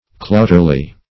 Search Result for " clouterly" : The Collaborative International Dictionary of English v.0.48: Clouterly \Clout"er*ly\, a. [From Clout , n.] Clumsy; awkward.